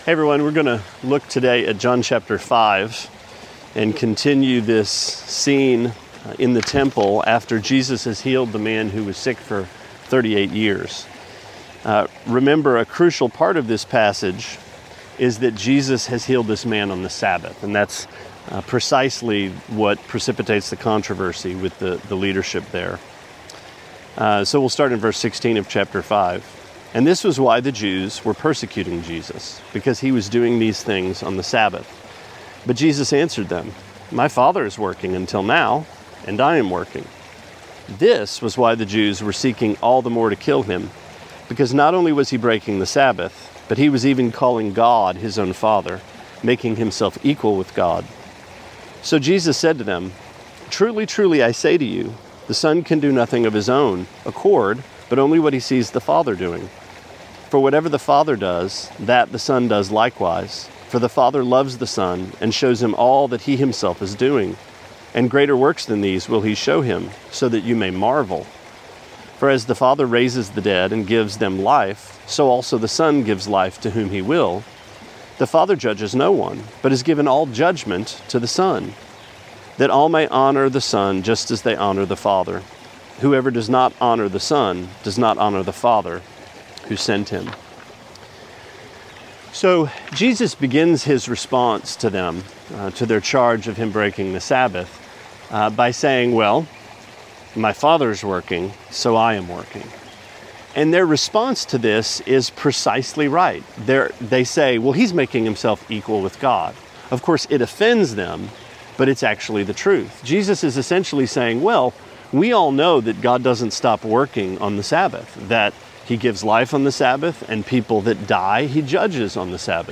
Sermonette 4/28: John 5:15-23: Family Business